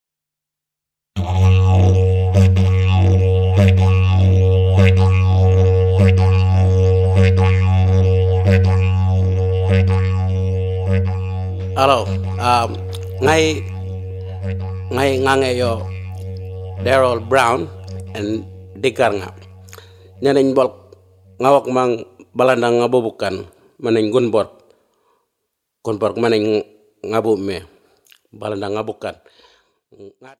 The Mago - This instrument (the didjeridu) is known as Mago in the Mayali language group of Western and Southern Arnhem Land.
Alternative,Indigenous